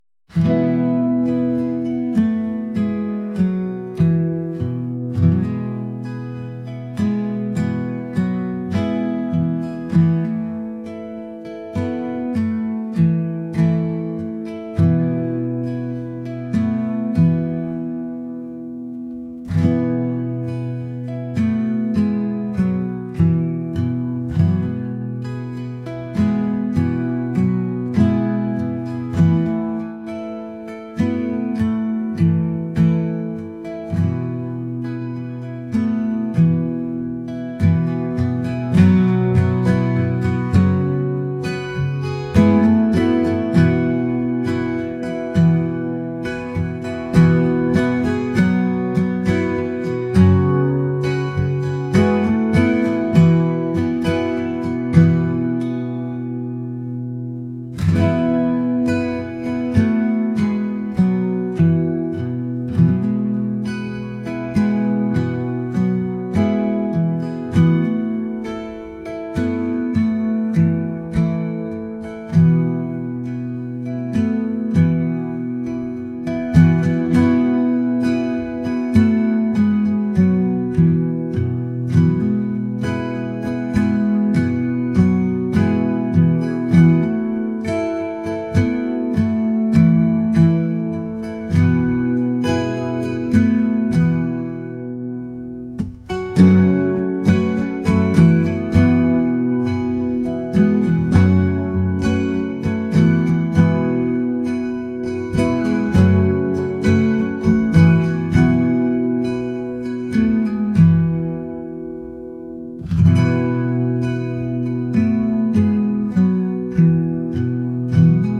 acoustic | indie | folk